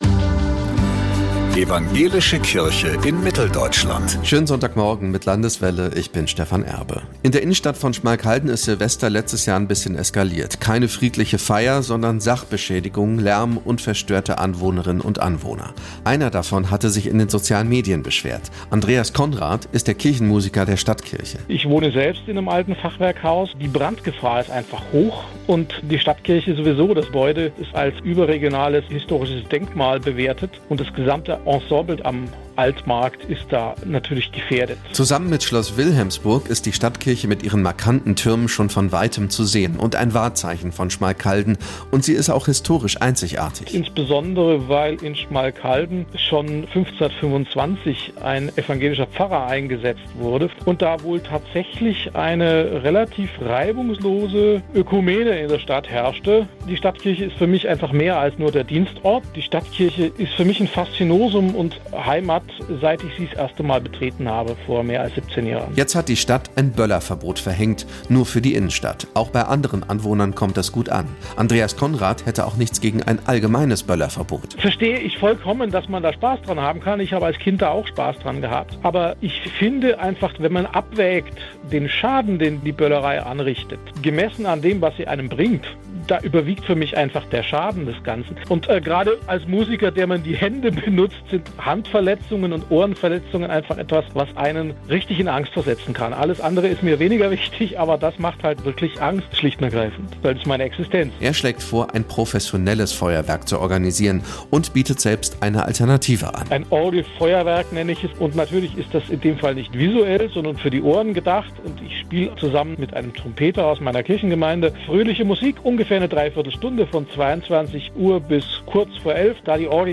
Antenne Thüringen 28.12.2025 07:15 Orgelfeuerwerk und Böllerverbot In der Innenstadt von Schmalkalden ist Silvester letztes Jahr ein bisschen eskaliert.